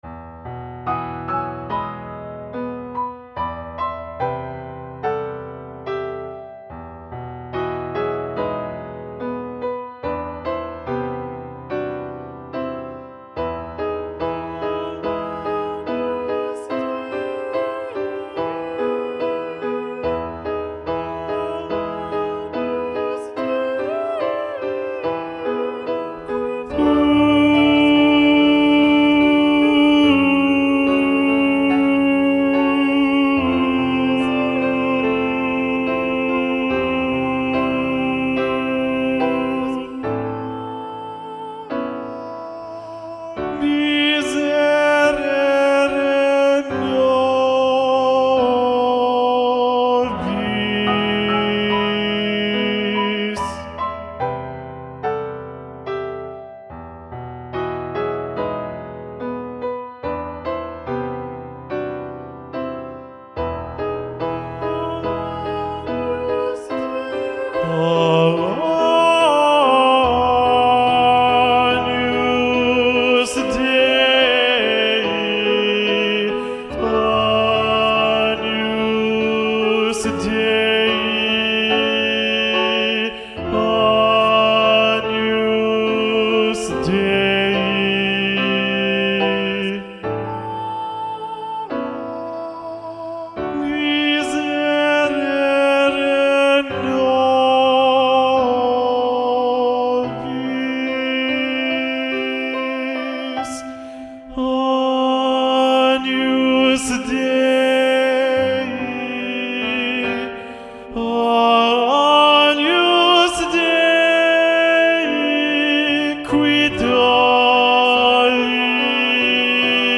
Lo ULTIMO Tenores
Agnus-Dei-Missa-Festiva-SATB-Tenor-Predominant-John-Leavitt.mp3